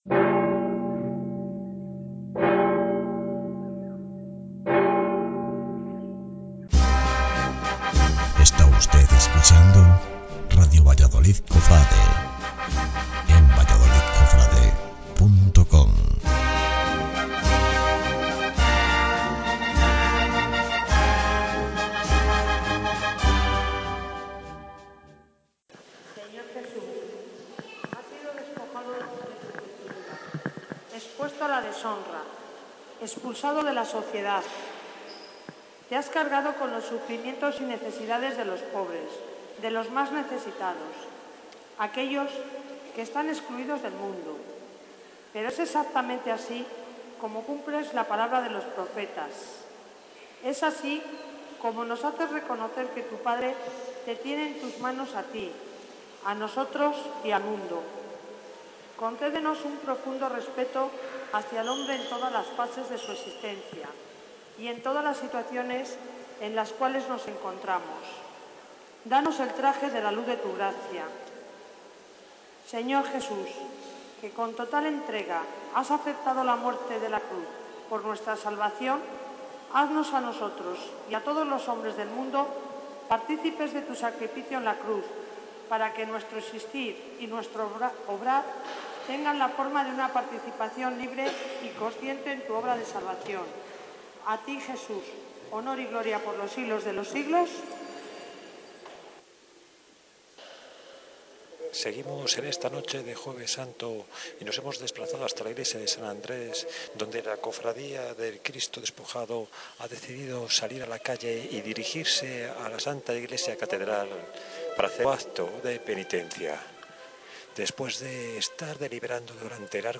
Comienzo de la Procesión del Santísimo Cristo Despojado, Cristo Camino del Calvario y Ntra. Sra. de la Amargura